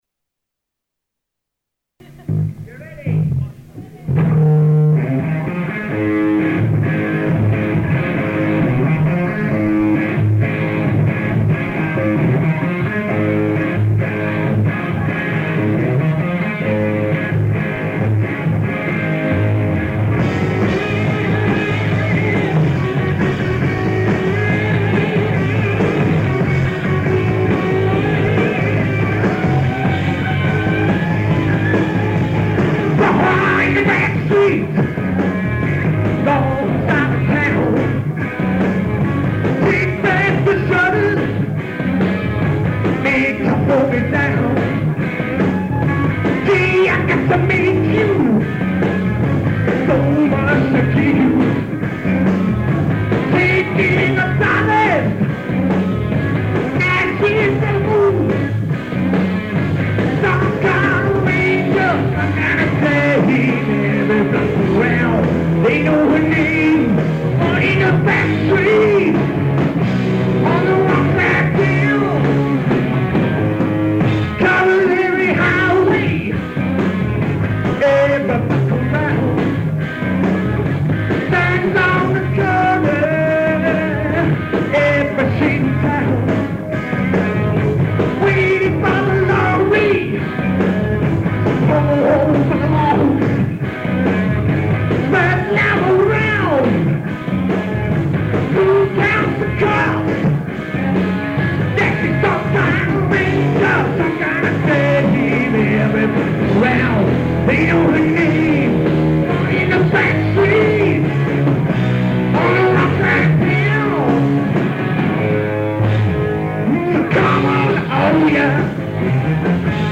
Questors theatre 1975